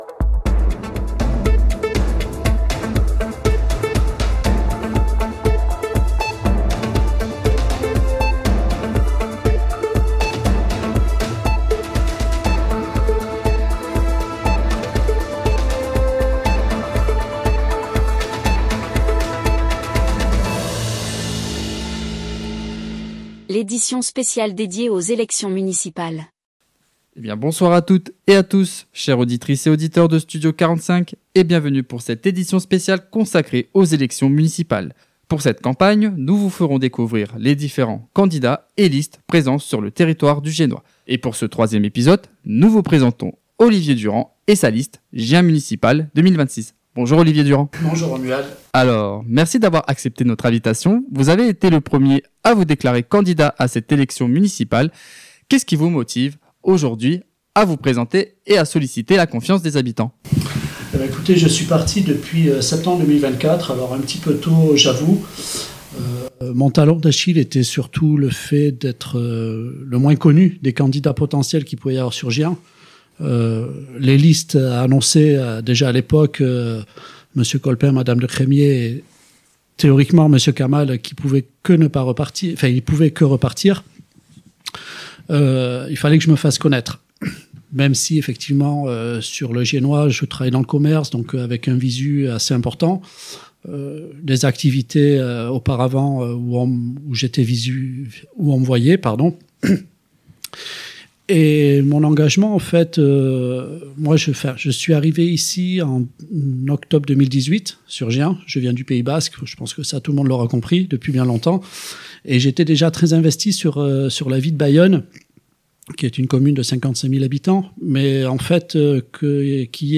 Cadre de vie, dynamisme économique, services de proximité, mobilité, vie associative… une interview pour mieux comprendre sa vision et ses propositions pour l’avenir de notre territoire.